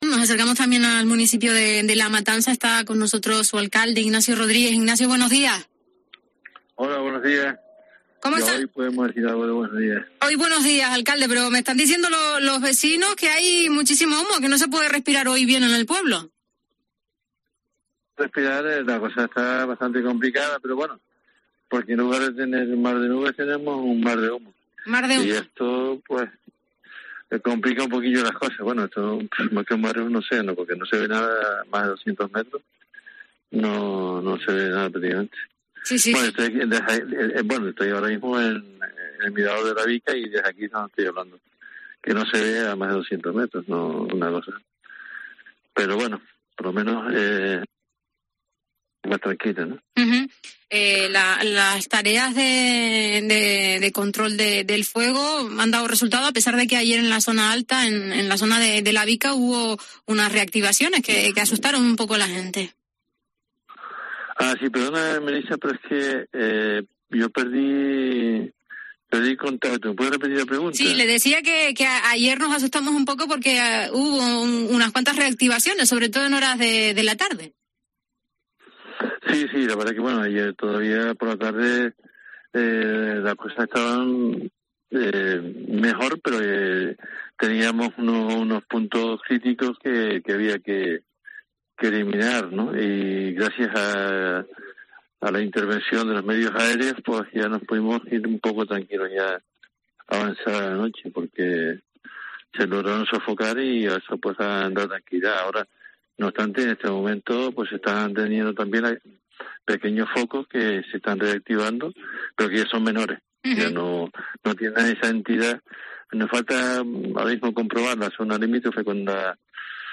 El alcalde de La Matanza de Acentejo, I gnacio Rodríguez , ha analizado en La Mañana de COPE Canarias la evolución del incendio en su municipio. El regidor ha destacado los complicados momentos que se vivieron en la madrugada del viernes al sábado en la zona alta de La Vica con varios focos fuera de control y falta de medios para hacer frente al fuego.